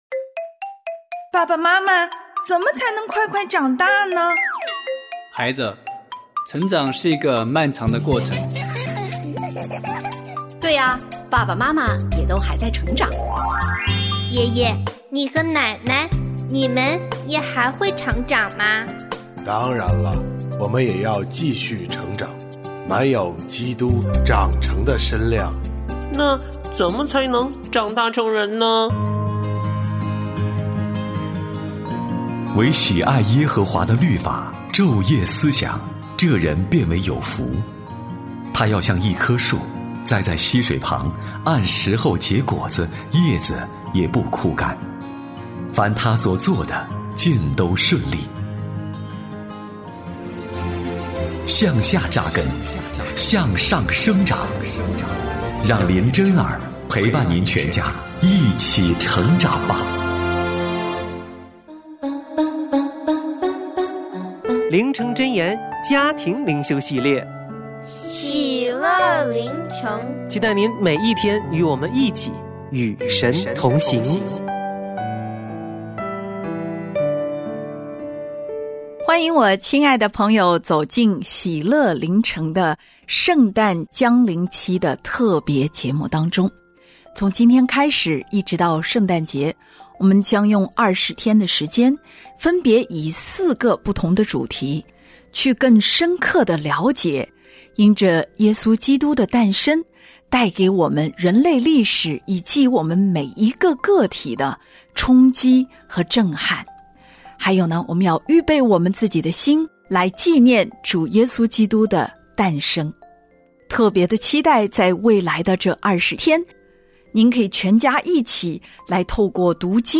我家剧场：圣经广播剧（142）摩押人正面迎战；以利沙帮助寡妇还债